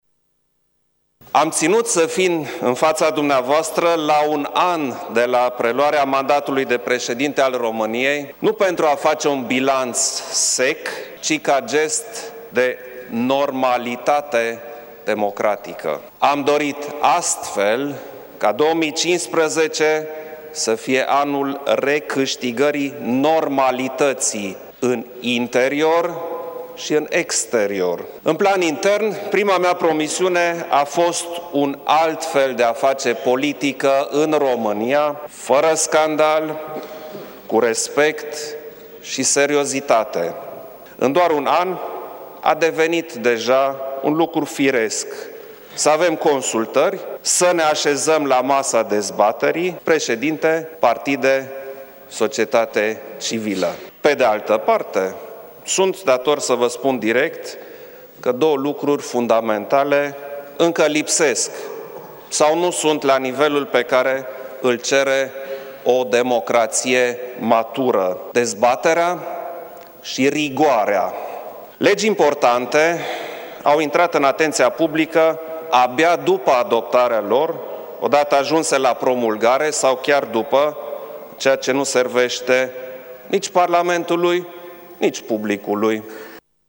Preşedintele Iohannis s-a adresat Parlamentului la împlinirea unui an de mandat
Președintele României, Klaus Iohannis:
iohannis-discurs.mp3